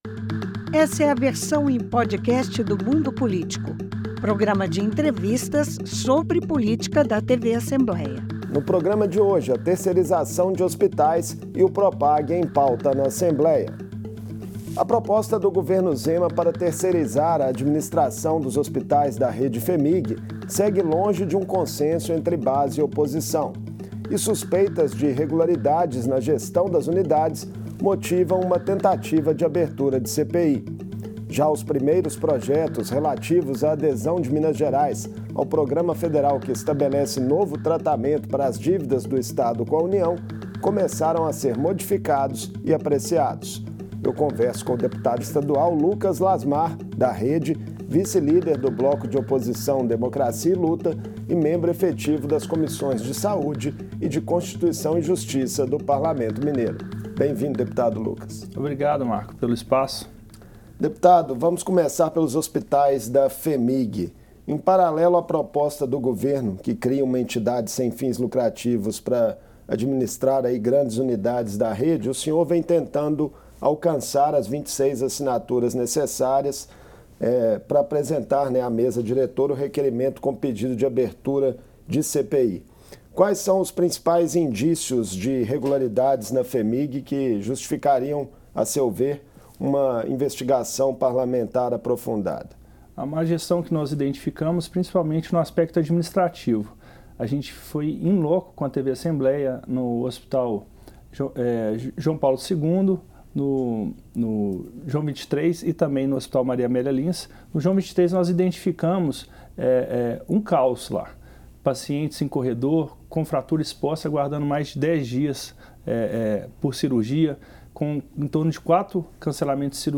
Uma proposta do governador Romeu Zema pretende criar uma entidade para gerenciar hospitais da Fundação Hospitalar de Minas Gerais (Fhemig). O vice-líder do bloco “Democracia e Luta”, deputado Lucas Lasmar (REDE), falou no Mundo Político sobre as estratégias da oposição para obstruir o projeto nas comissões e no Plenário. O deputado pretende criar uma CPI sobre o sucateamento estrutural e funcional das unidades hospitalares da Fhemig, mas ainda não atingiu as assinaturas suficientes.